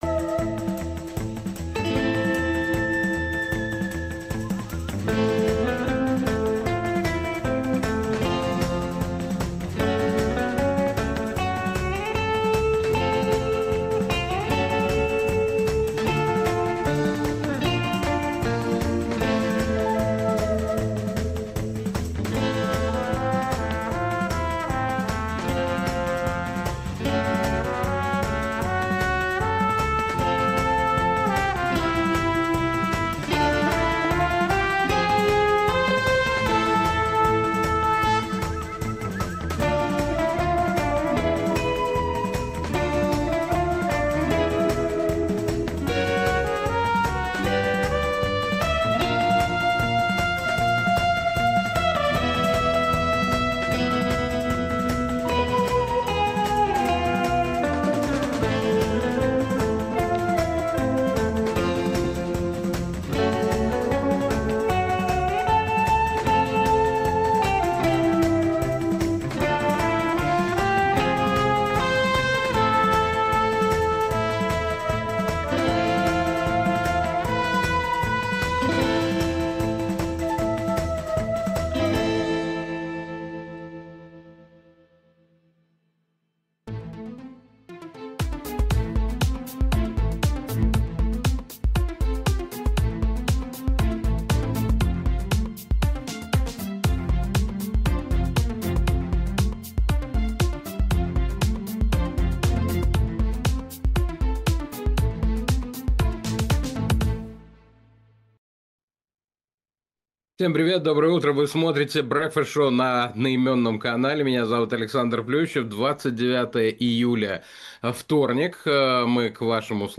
Александр Плющев обсудит с экспертами в прямом эфире The Breakfast Show все главные новости.